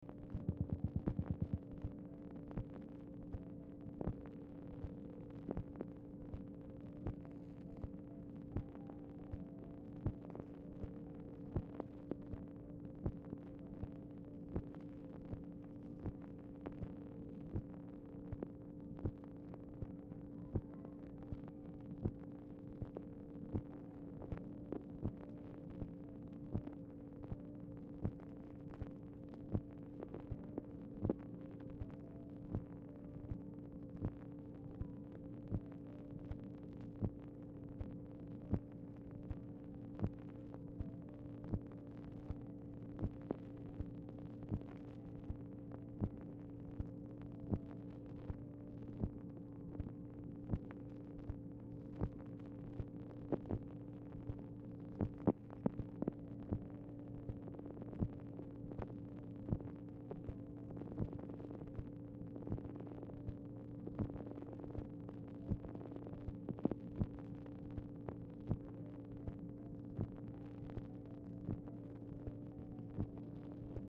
Telephone conversation # 5503, sound recording, OFFICE NOISE, 9/5/1964, time unknown | Discover LBJ
Format Dictation belt
Location Of Speaker 1 Mansion, White House, Washington, DC